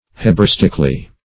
Search Result for " hebraistically" : The Collaborative International Dictionary of English v.0.48: Hebraistically \He`bra*is"tic*al*ly\, adv. In a Hebraistic sense or form.